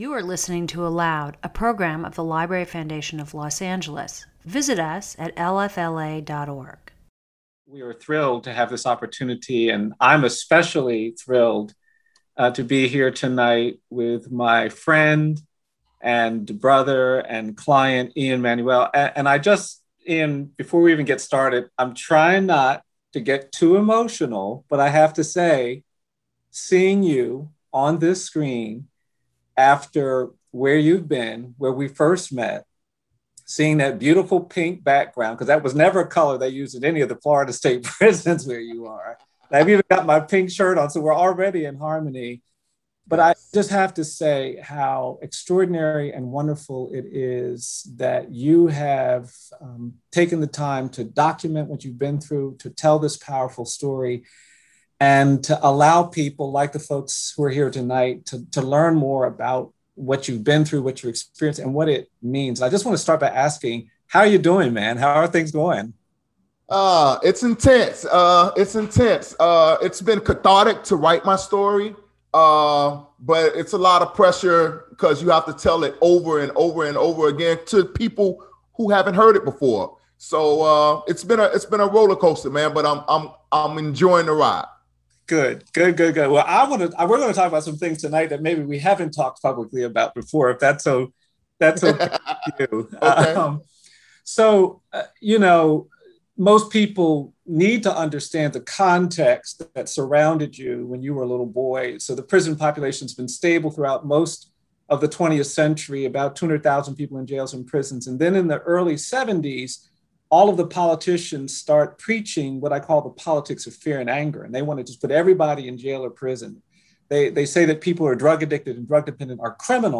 In Conversation With Bryan Stevenson